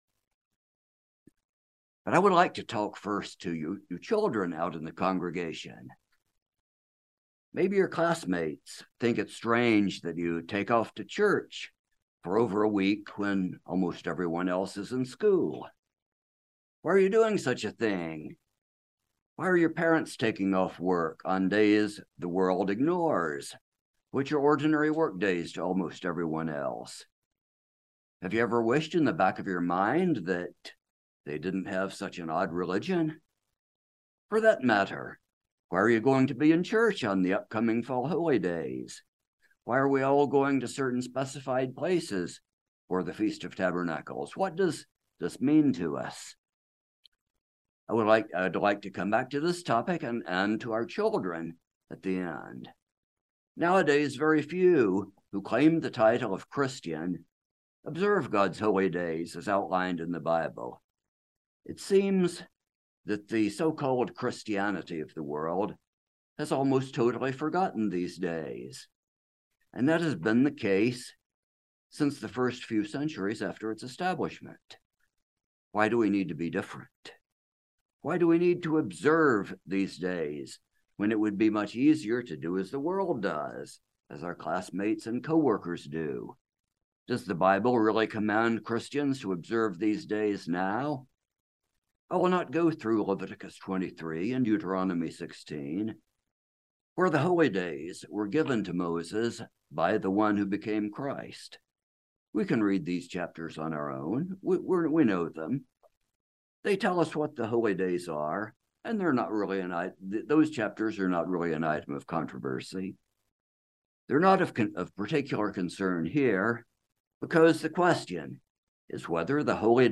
This message gives several Biblical proofs that observance of the annual Holy Days is binding on New Testament Christians, not only Israelite Christians but also Gentile Christians.